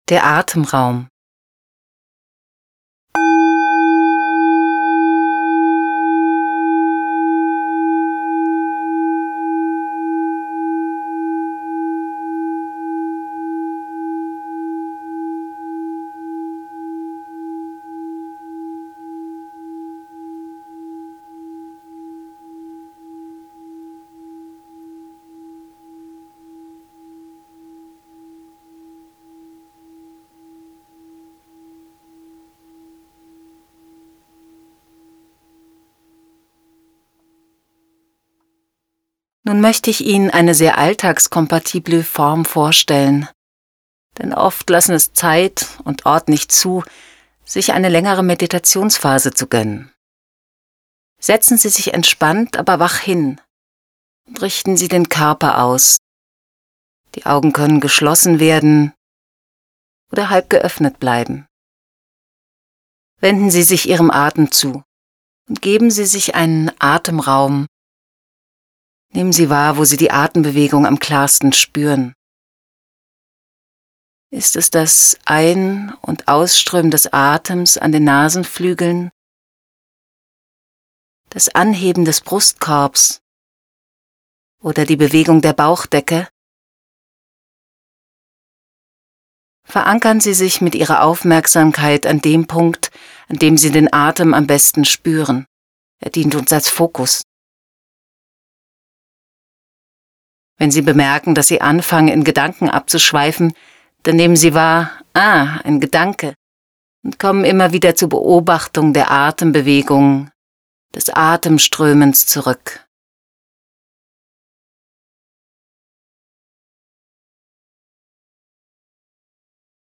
wache-schule-hoerprobe-cd.mp3